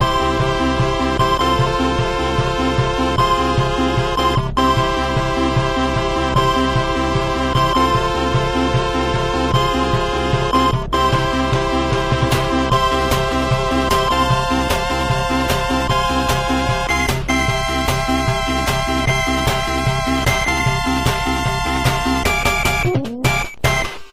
SEGA Master System 2 Startup.wav